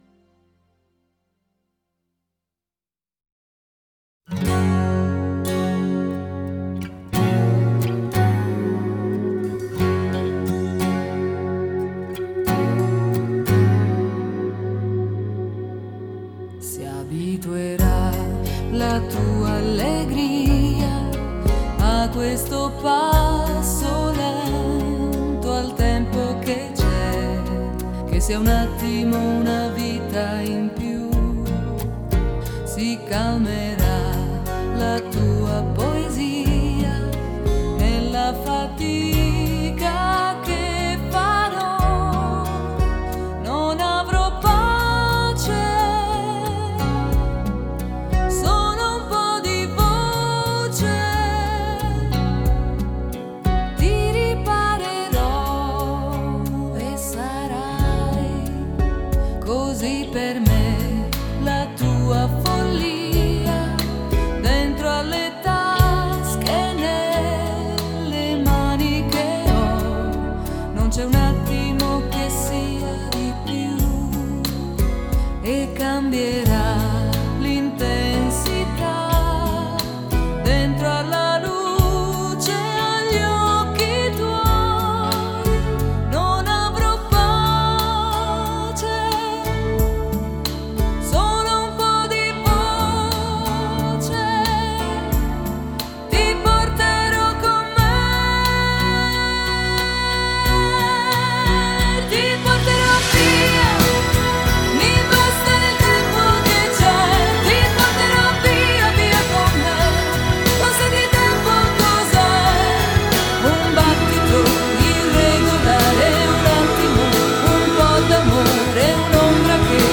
Жанр: Italo Pop